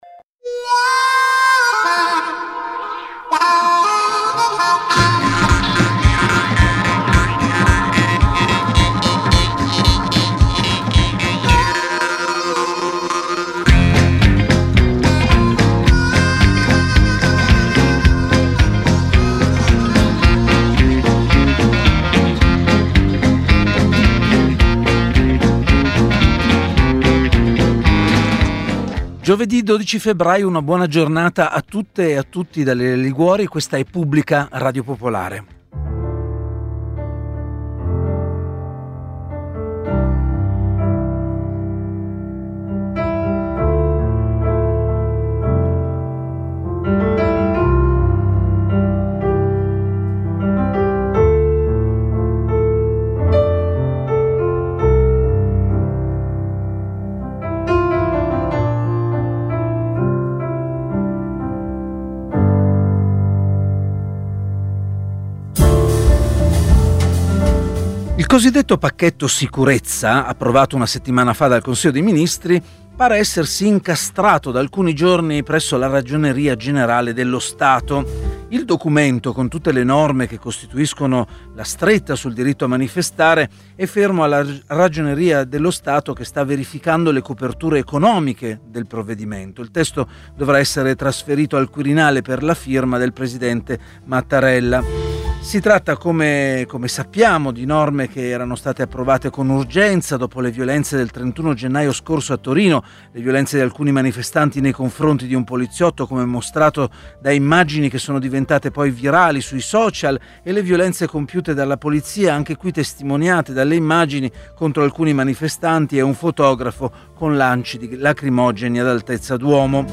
Pubblica ha ospitato oggi anche Pietro Grasso (ex procuratore nazionale antimafia ed ex presidente del Senato) che ha parlato del suo ultimo libro («U Maxi», sul maxiprocesso a cosa nostra, Feltrinelli), ma anche del pacchetto sicurezza così come del referendum sulla magistratura.